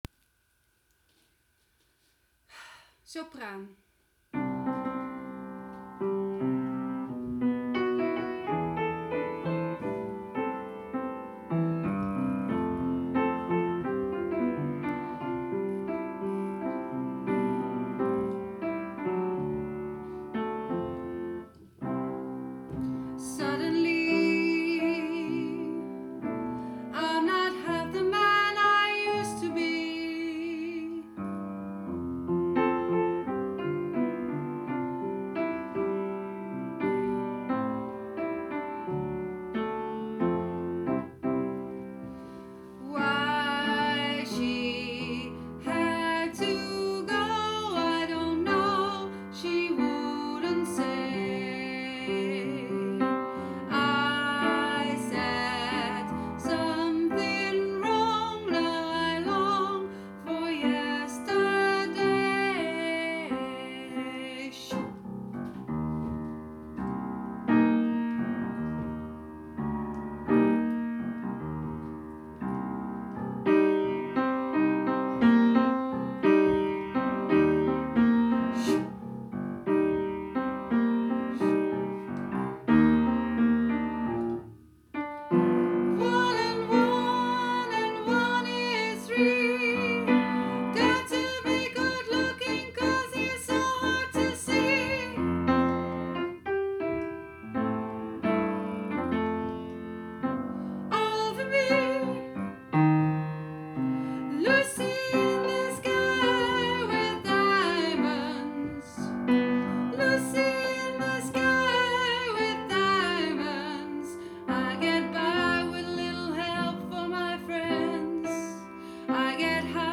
Beatles_sopranen.m4a